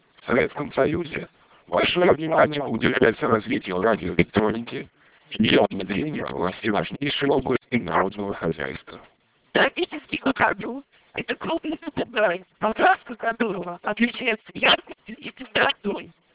Для сравнения ниже приведены данные, полученные в аналогичных условиях для стандартного MELP вокодера федерального стандарта США со скоростью 2400 бит/с:
Канал без группирования ошибок, BER = 5%
melp2400_awgn_5.wav